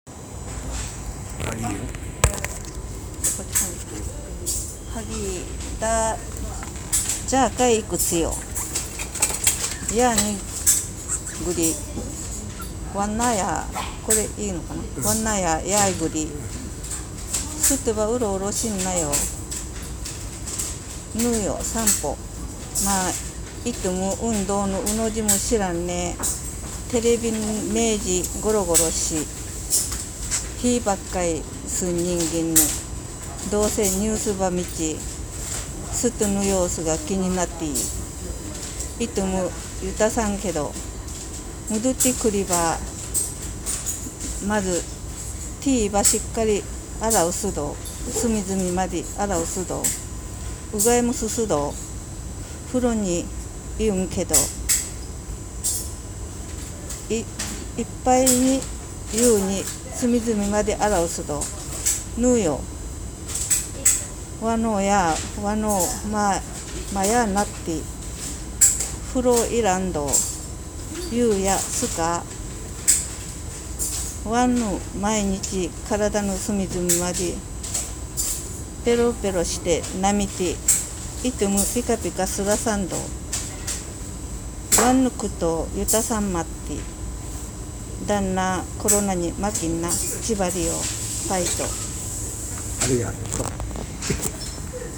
喜界島阿伝の方に翻訳してもらいました。
お願いして普段使っていませんが思い出しながら喋ってもらいました。